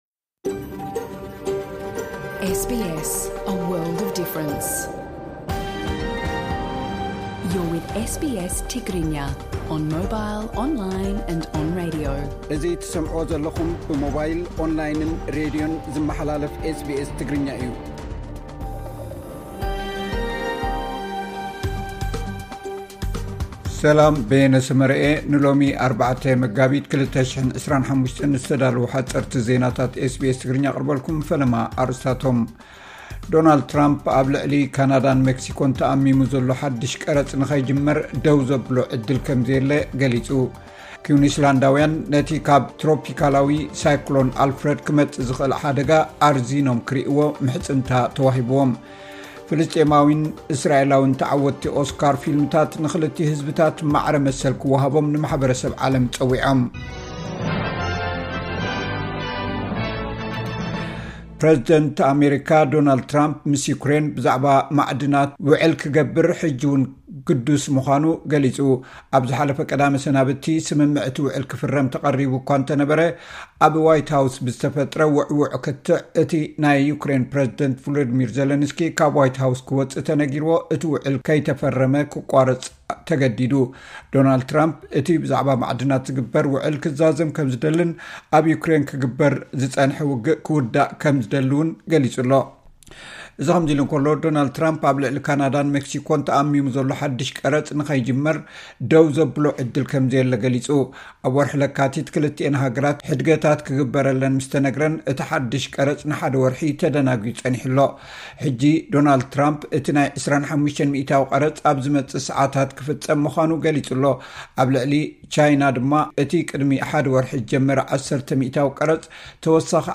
ሓጸርቲ ዜናታት ኤስ ቢ ኤስ ትግርኛ (04 መጋቢት 2025)